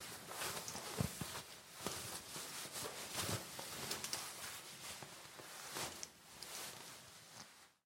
Звук, когда девушка поправляет чулки, панталоны или трусы